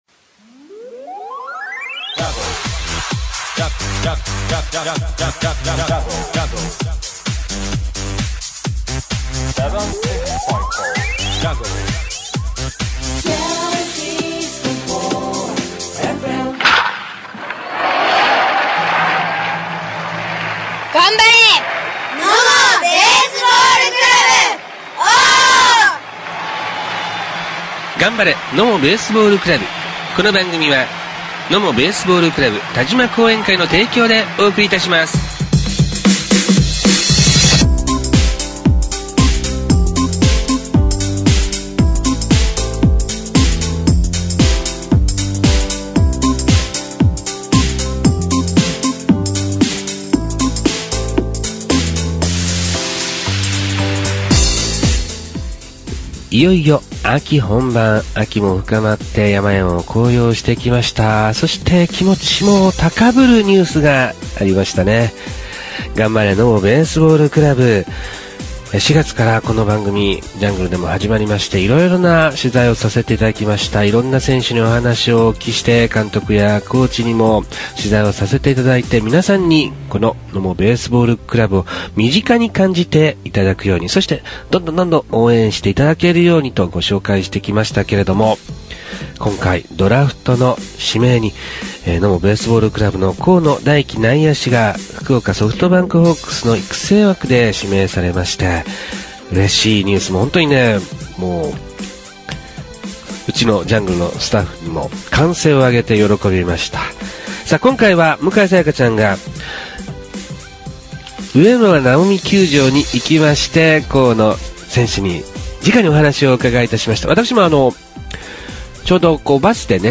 植村直己球場で練習後にインタビューをさせていただきました。